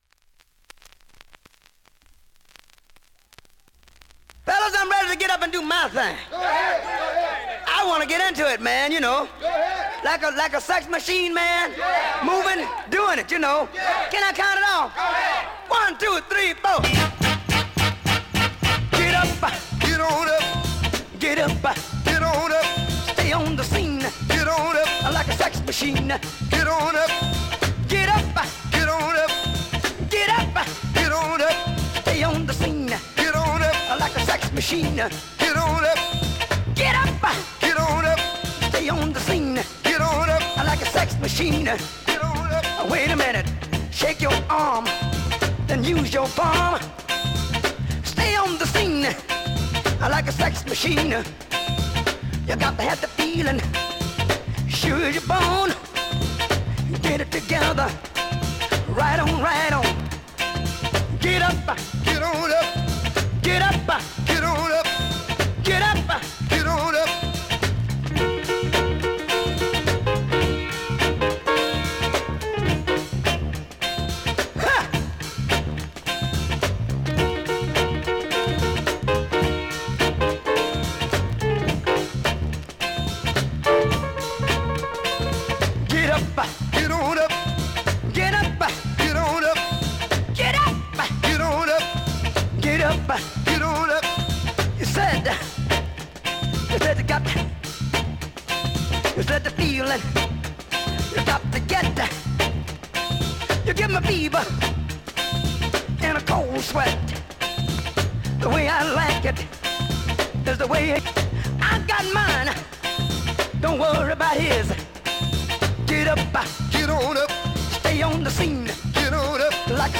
現物の試聴（両面すべて録音時間６分）できます。